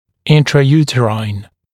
[ˌɪntrəˈjuːtəraɪn, -rɪn][ˌинрэˈйу:тэрайн, -рин]внутриматочный, внутриутробный